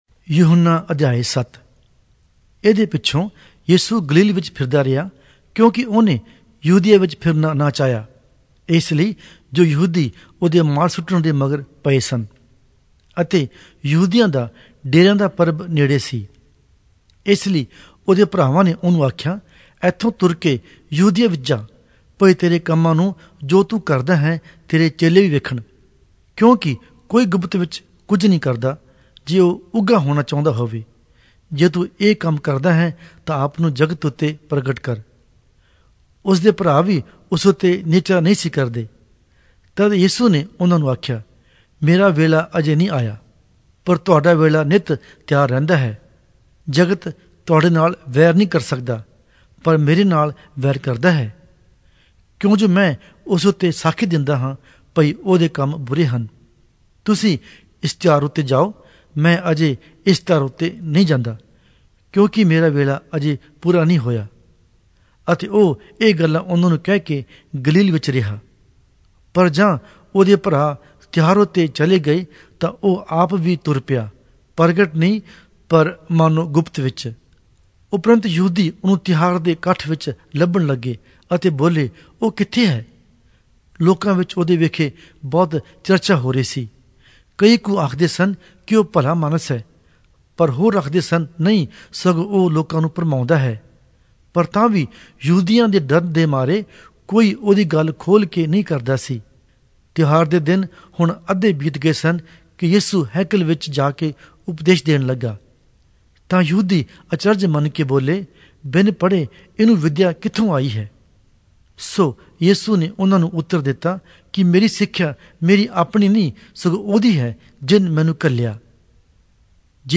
Punjabi Audio Bible - John 21 in Ocvkn bible version